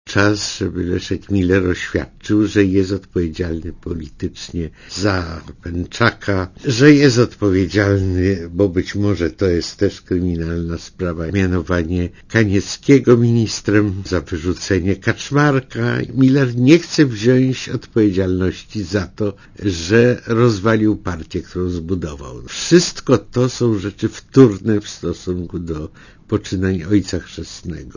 Posłuchaj komentarza Jerzego Urbana